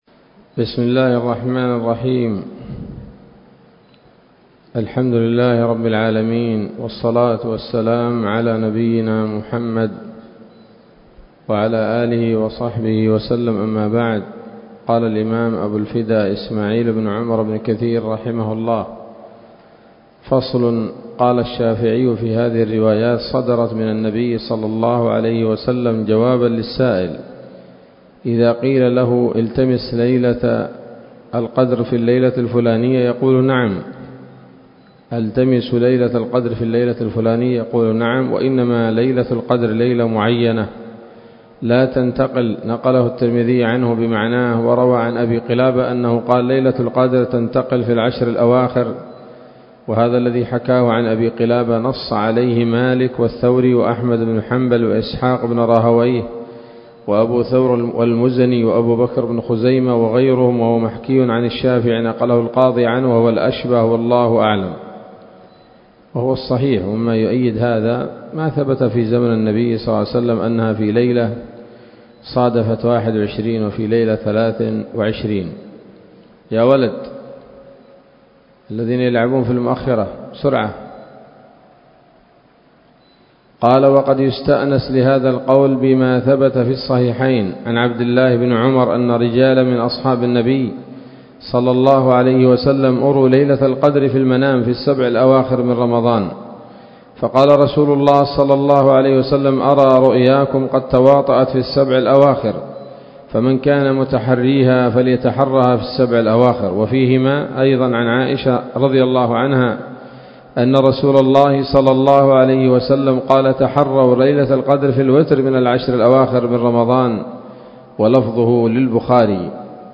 الدرس الرابع وهو الأخير من سورة القدر من تفسير ابن كثير رحمه الله تعالى